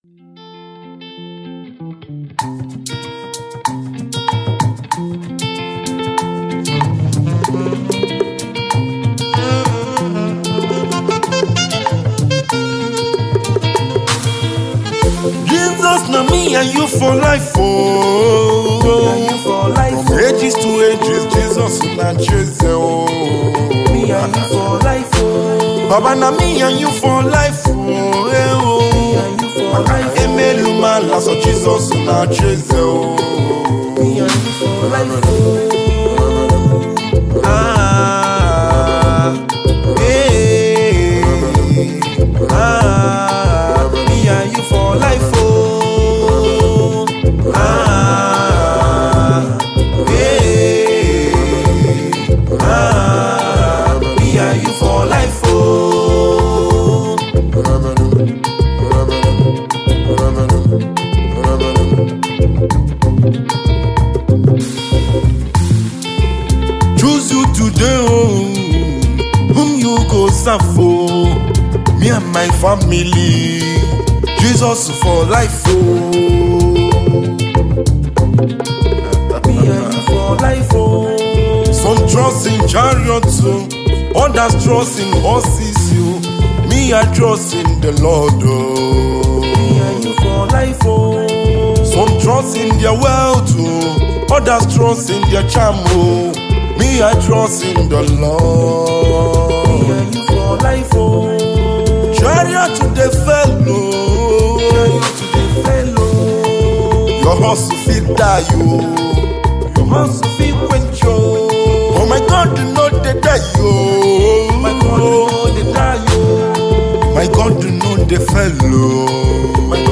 gospel music
songwriter and saxophonist.
heavily blended in traditional African rhythms